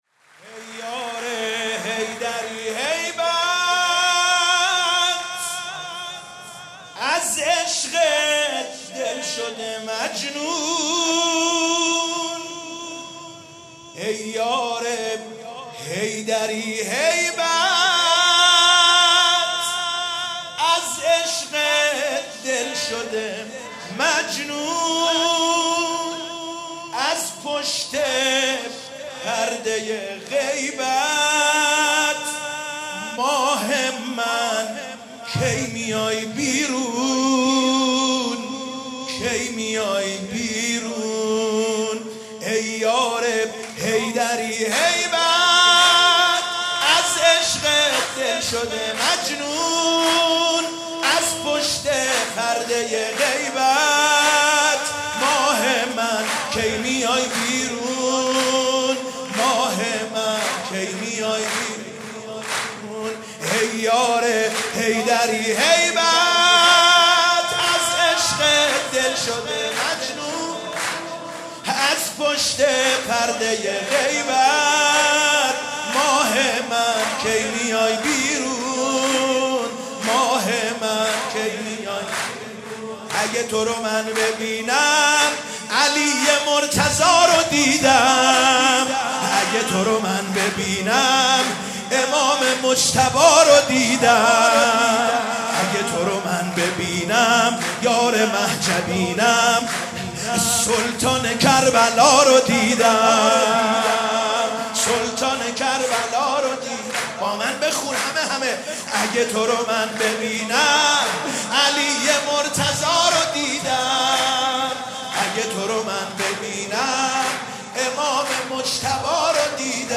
ولادت امام زمان علیه السلام